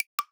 notifier_clicka.opus